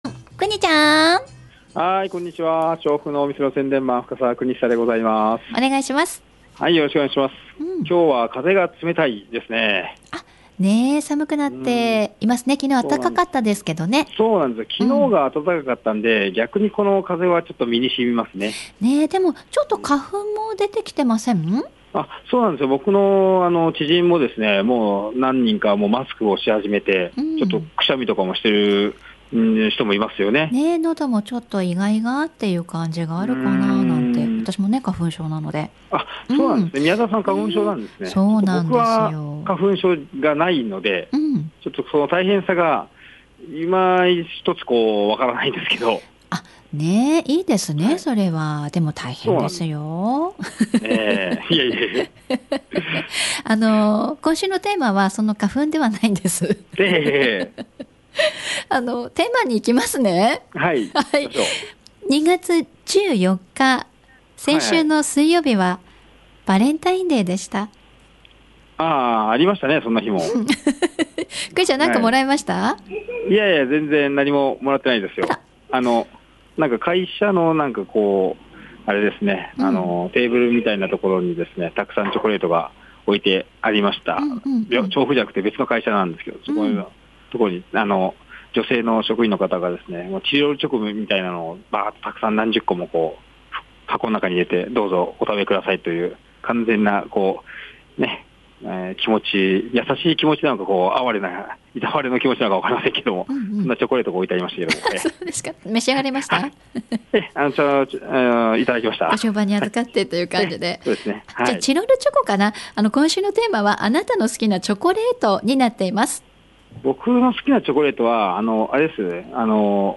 ここ最近は天神通り店からの中継ばかりでしたが 今回は本店です。